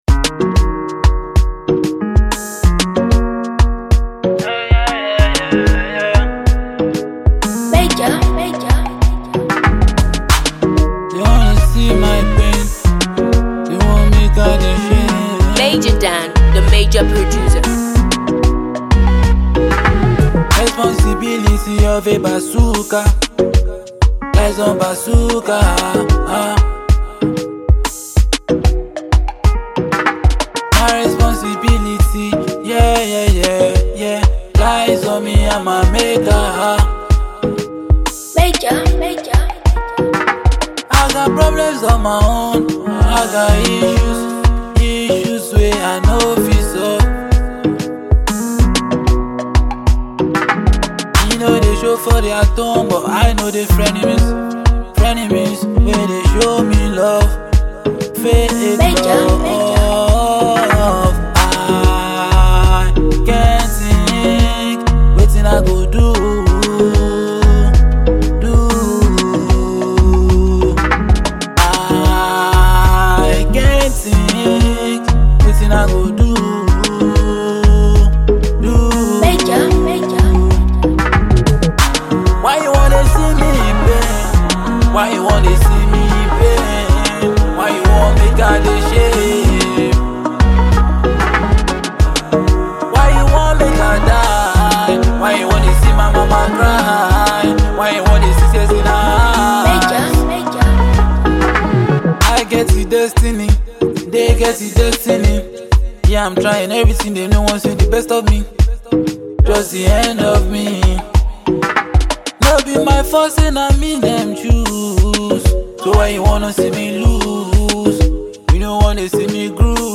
melodious and Inspirational